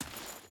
Dirt Chain Walk 5.ogg